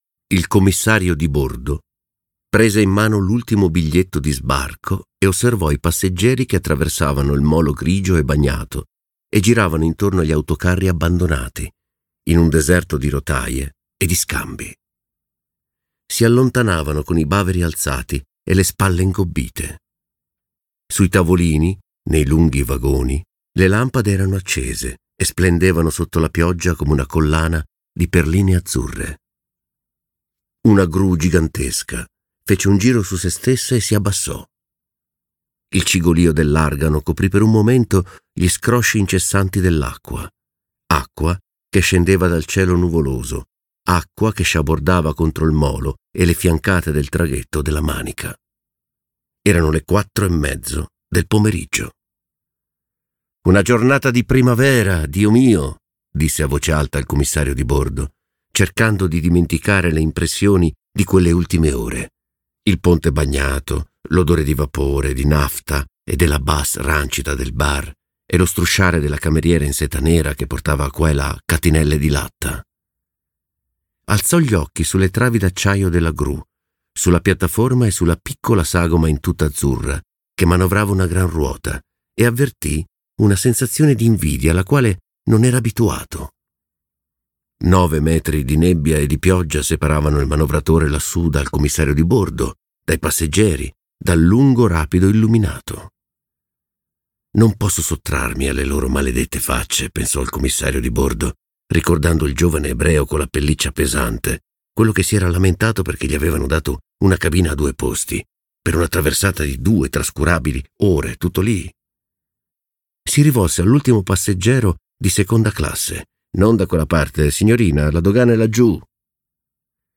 letto da Paolo Pierobon
Versione audiolibro integrale